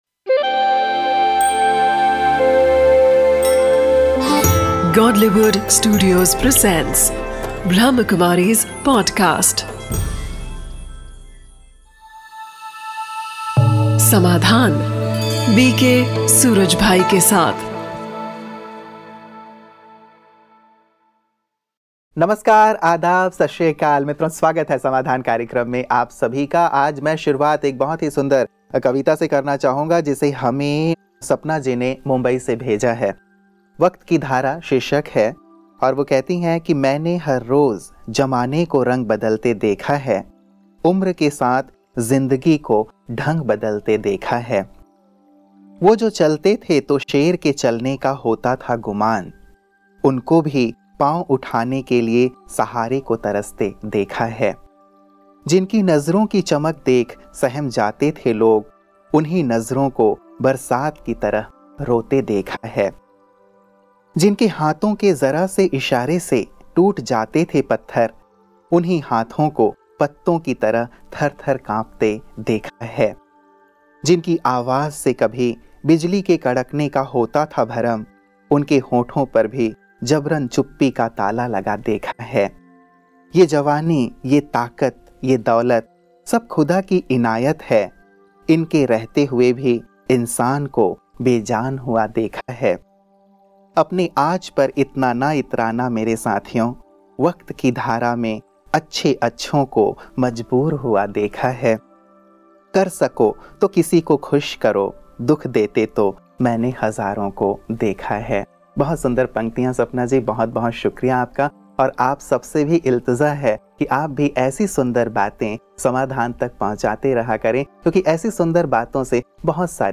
'Amrut Vani' is a collection of invaluable speeches of our dearest Dadi Janki ji.